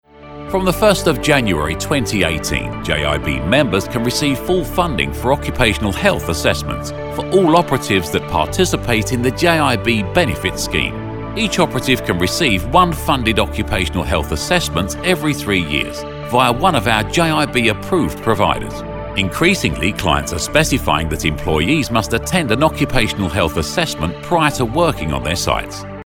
英式男1.5-mi磁性成熟（只
成熟稳重 英文配音